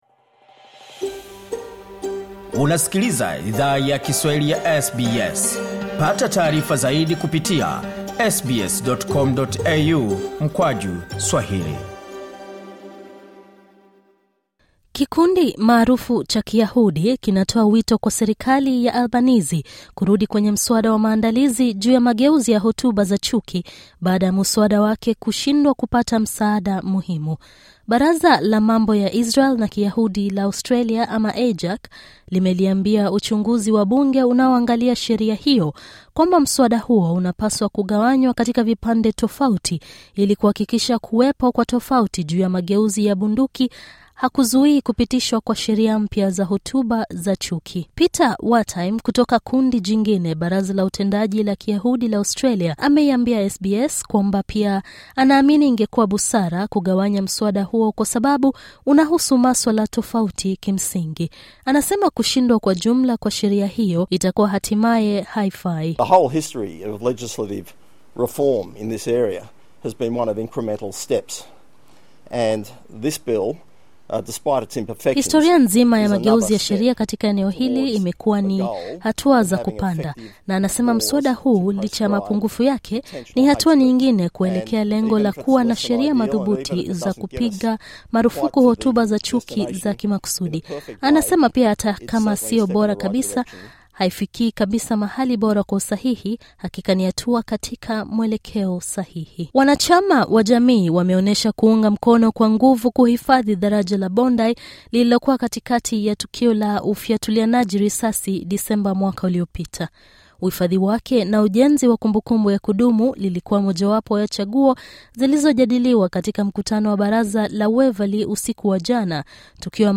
Taarifa ya habari:tume ya kifalme ya kuchunguza hotuba za chuki yakosa uungwaji mkono